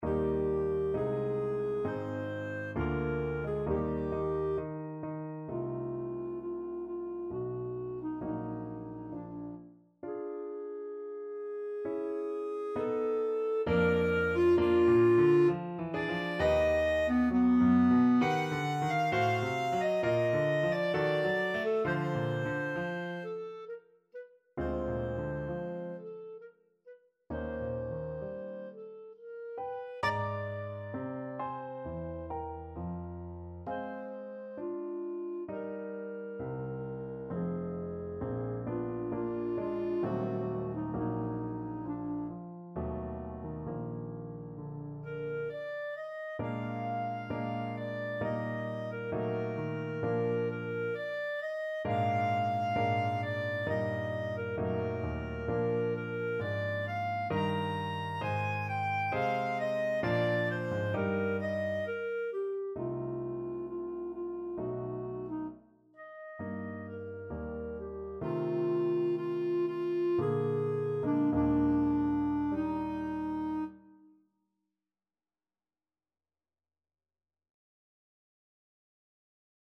3/4 (View more 3/4 Music)
=66 Andante sostenuto
B4-Bb6
Classical (View more Classical Clarinet Music)